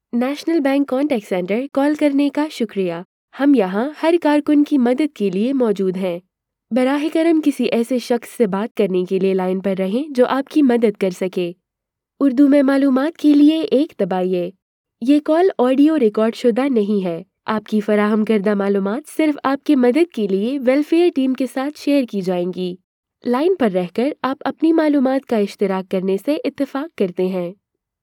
Urdu Voice Artists and Talents - Voice-overs by Adelphi Studio